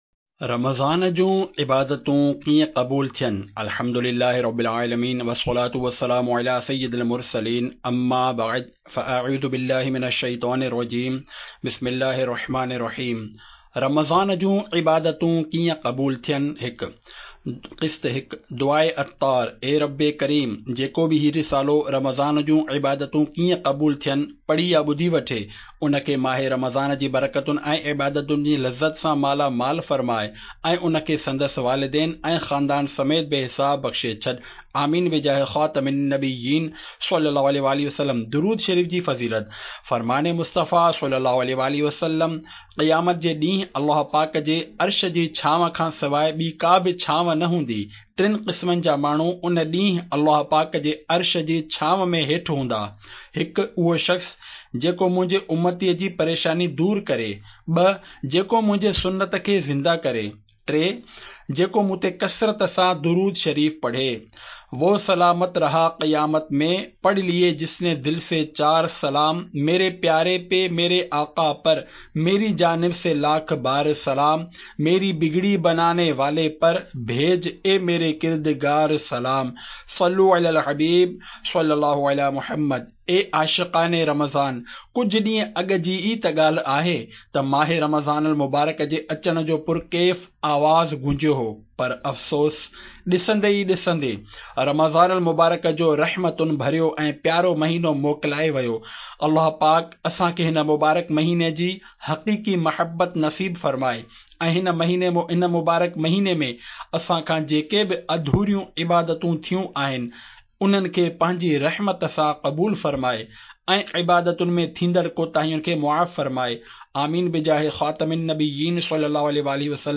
Audiobook - Ibadaat e Ramazan Kaese Qabool Ho?